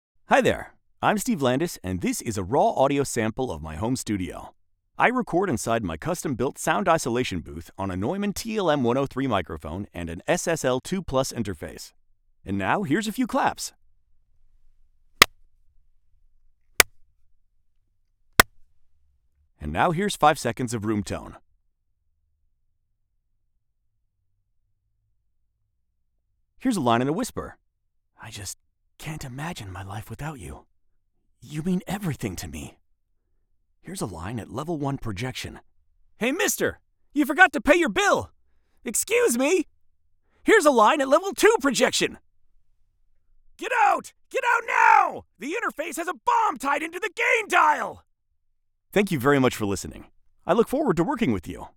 Middle-aged deep-voiced neutral/mid-western US accented voice talent with range on tone and
Raw studio sample